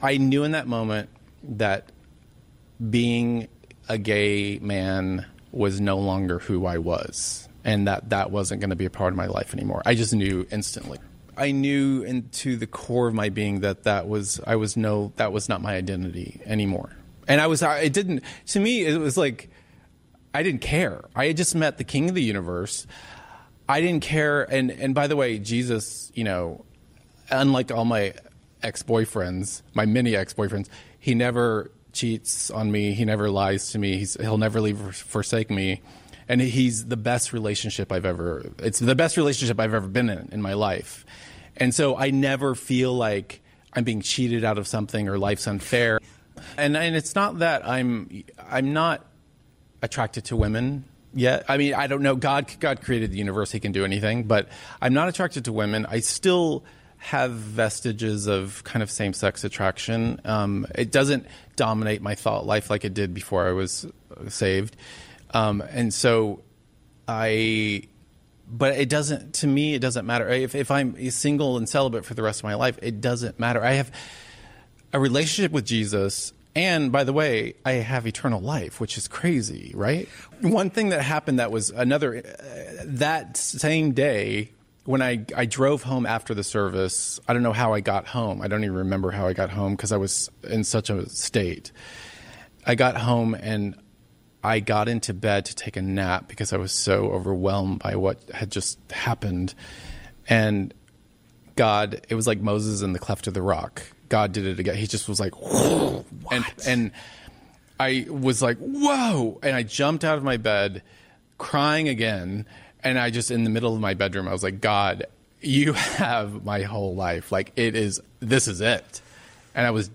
As we mentioned earlier, he shared his testimony on the Eric Metaxas show on August 8, 2019. You can hear a brief portion of that program here.